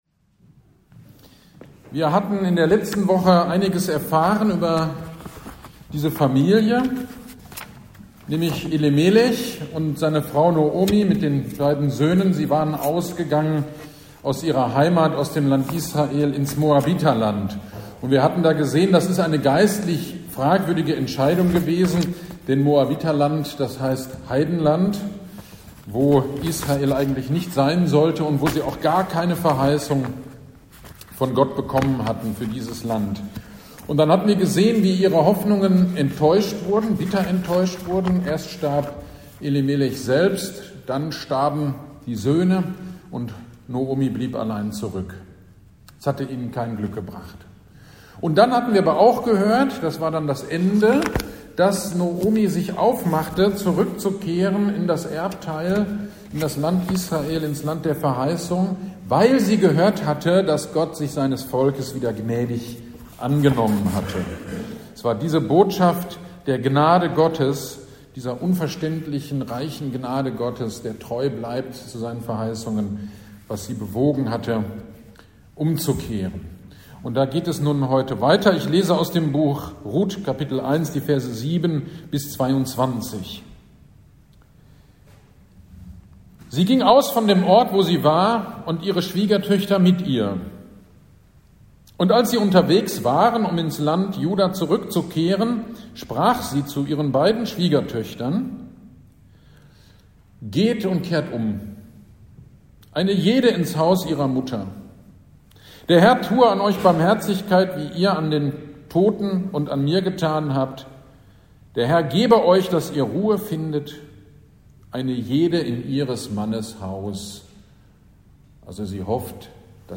GD am 07.05.23 Predigt zu Rut 1.7-22